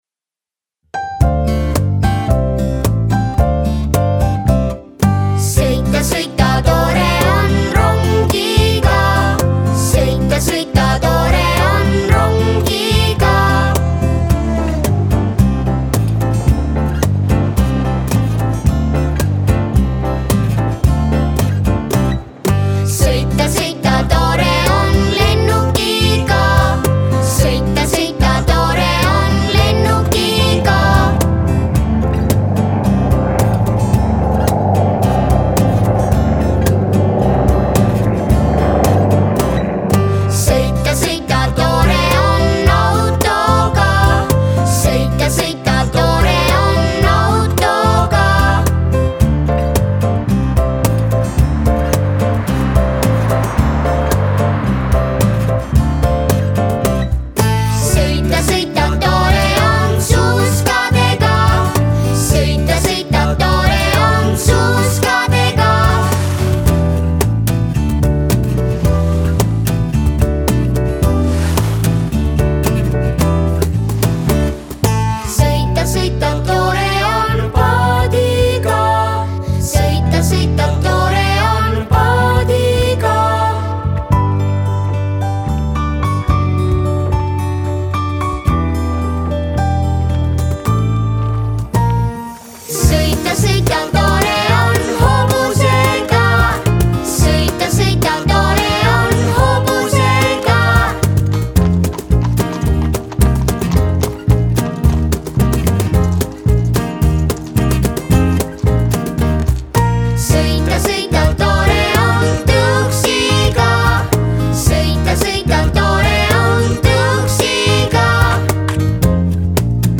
Originaalhelistik: C-duur
solistile ja 1-h taustvokaalile